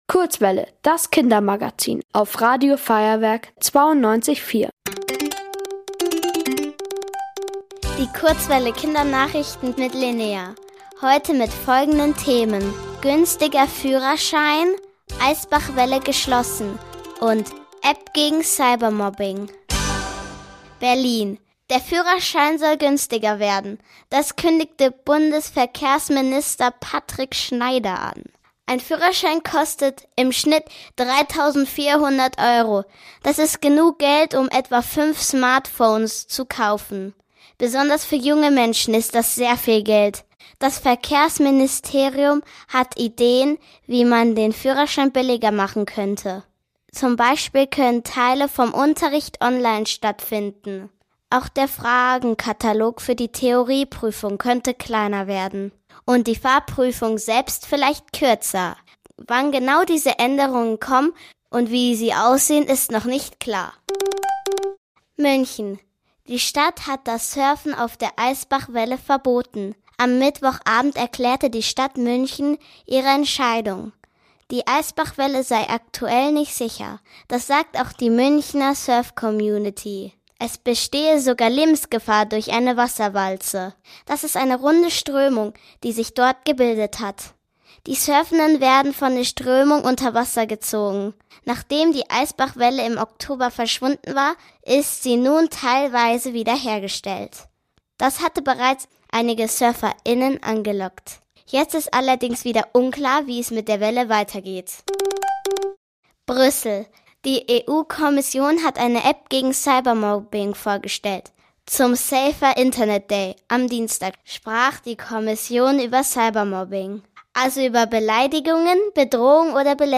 Die Kurzwelle Kindernachrichten vom 14.02.2026